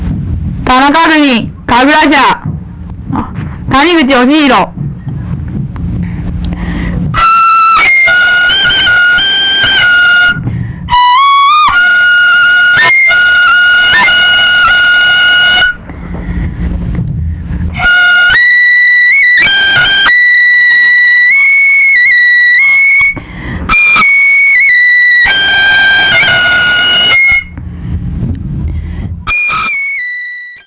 kagura.wav